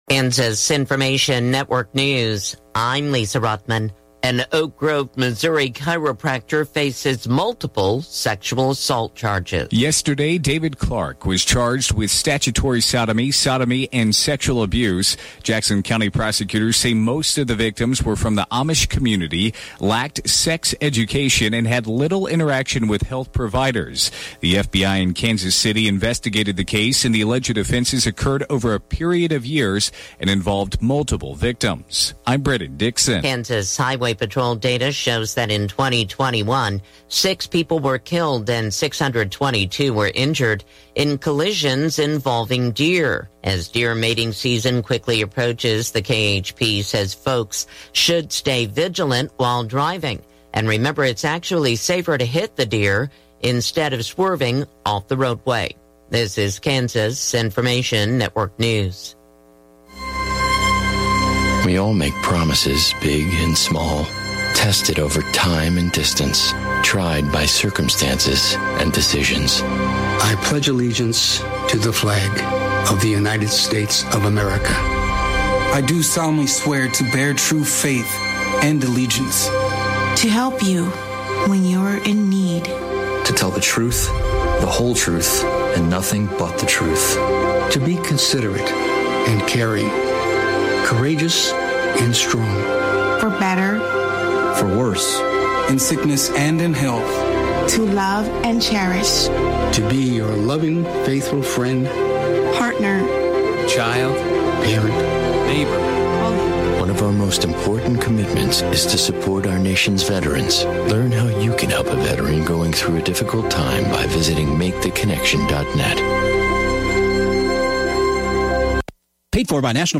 Classic Hits KQNK News, Weather & Sports Update – 9/26/2023